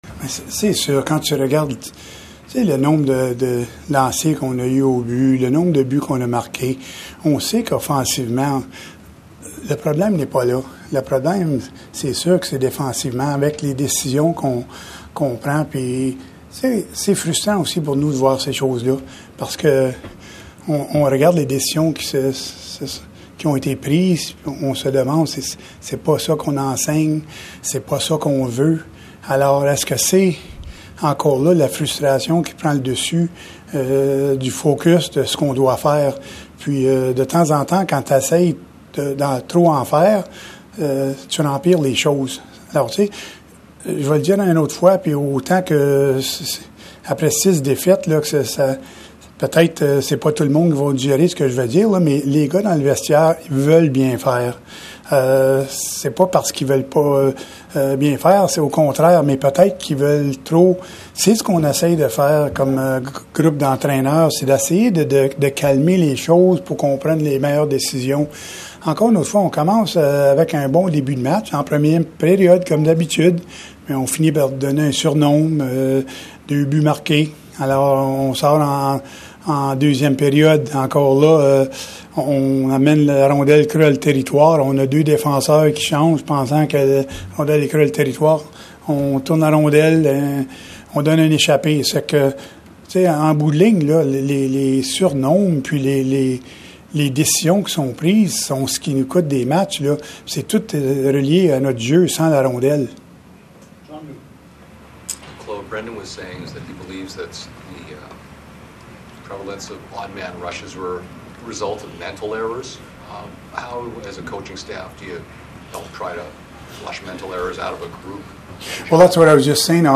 Claude Julien en point de presse suite à la défaite des siens face aux Devils du New Jersey